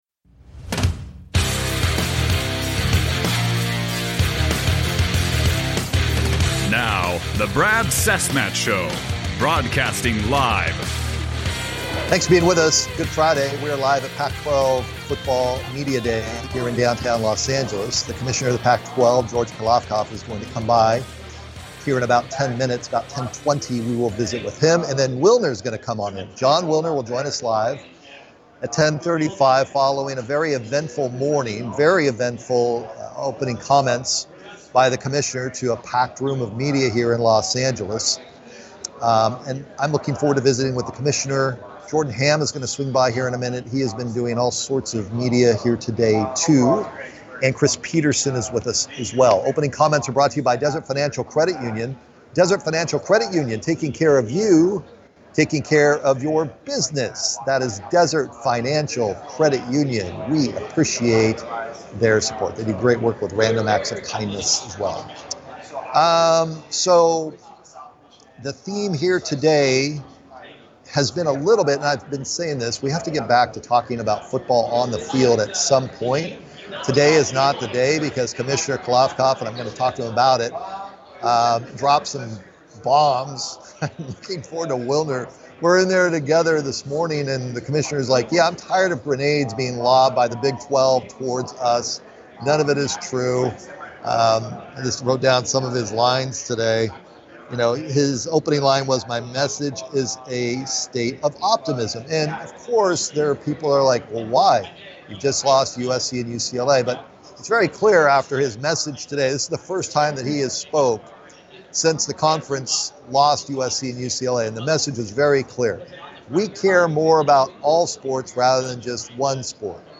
Live from Pac-12 Media Day